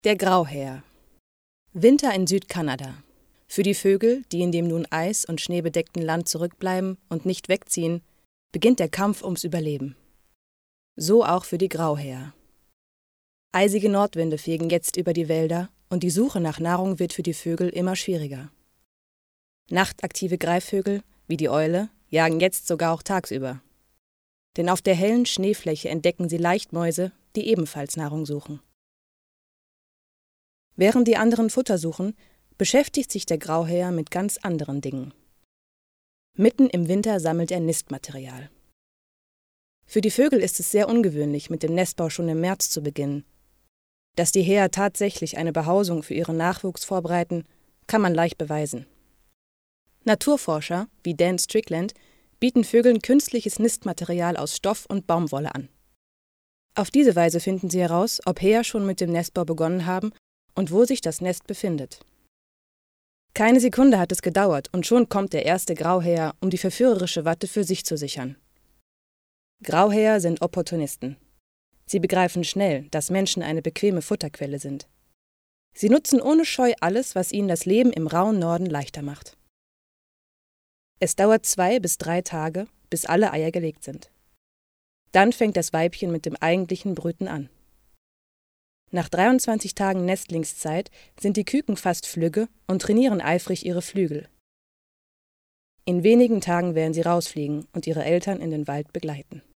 Junge Sprecherin deutsch
Sprechprobe: Sonstiges (Muttersprache):
Young German voice